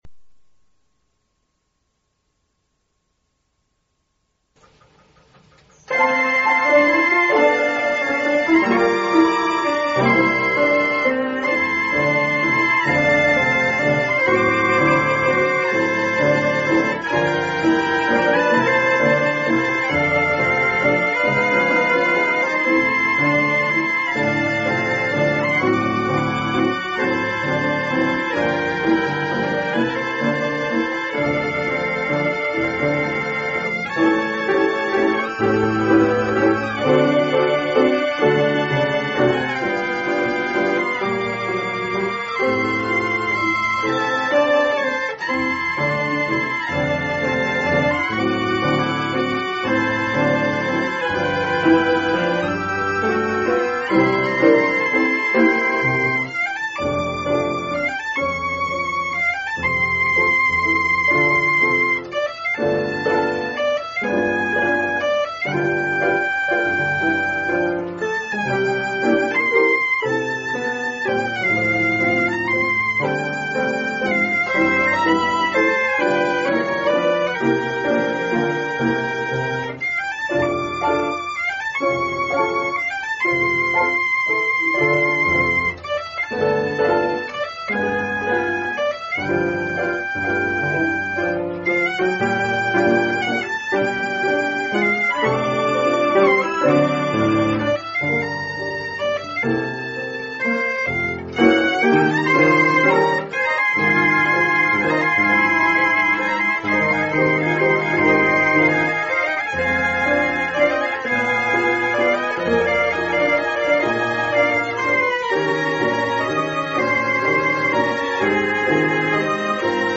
on a Mills Violano